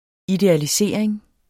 Udtale [ idealiˈseˀɐ̯eŋ ]